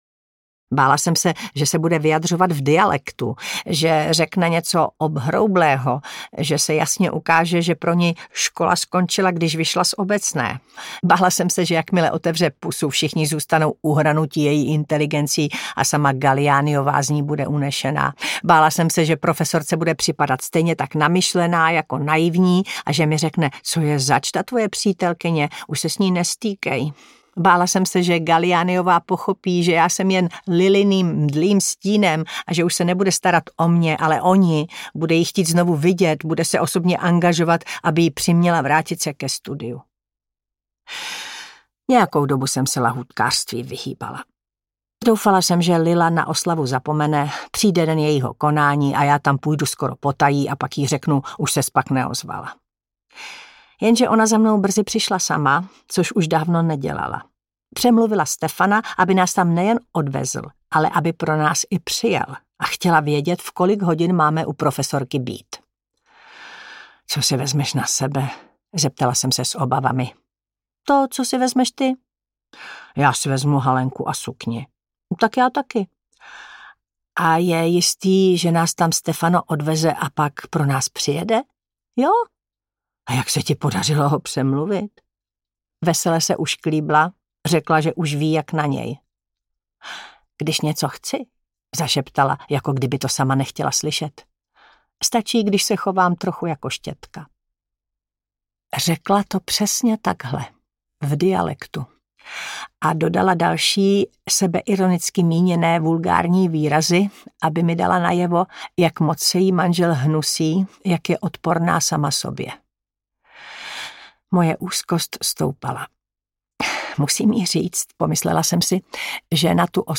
Příběh nového jména audiokniha
Ukázka z knihy
• InterpretTaťjana Medvecká